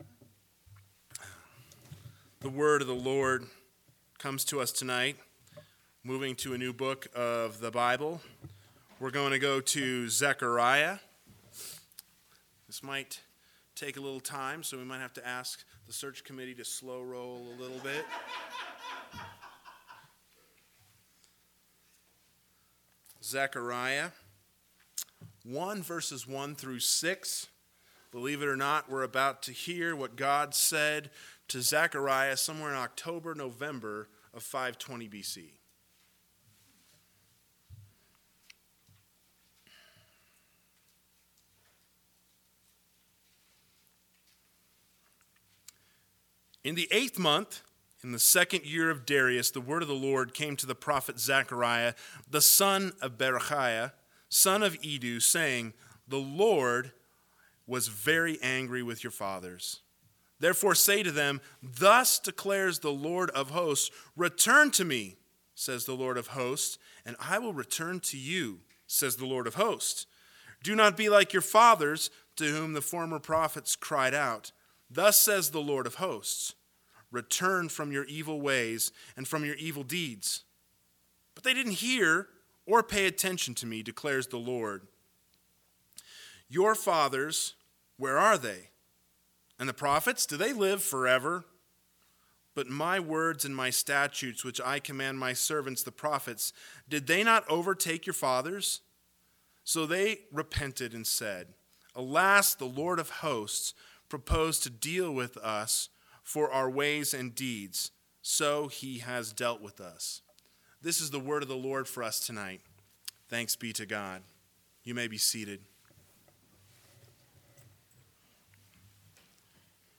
PM Sermon – 11/15/2020 – Zechariah 1:1-6 – Repent, The Lord Forgives!